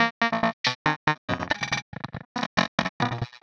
tx_perc_140_robots2.wav